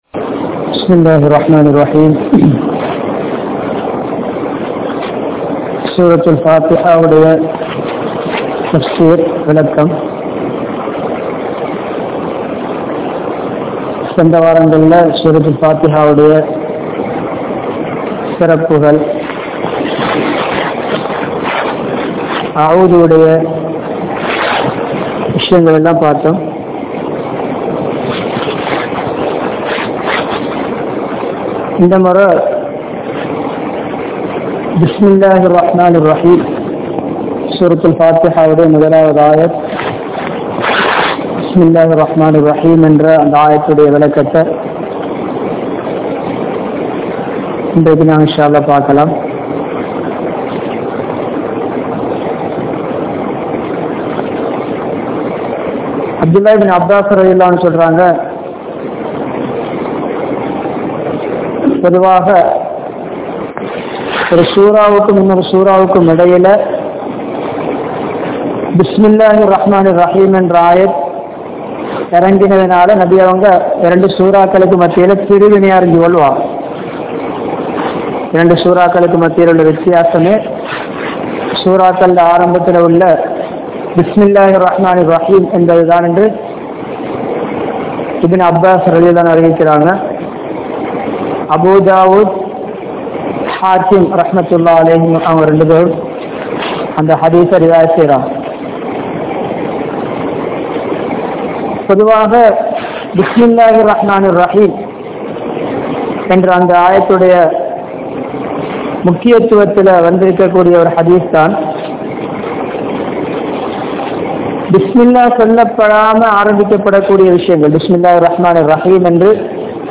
Sura Fathiha(Thafseer 02) | Audio Bayans | All Ceylon Muslim Youth Community | Addalaichenai
Colombo 15, Mattakuliya, Mutwal Jumua Masjidh